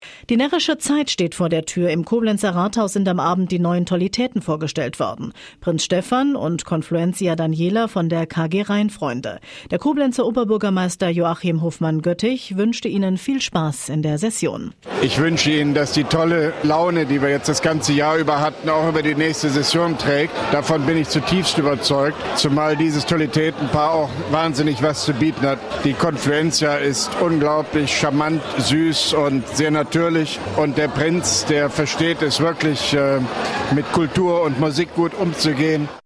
Ausschnitt: RPR1 Regional, Studio Koblenz, 8.30 Uhr, 03.11.2011
Mit Kurz-Interview des Koblenzer OB Hofmann-Göttig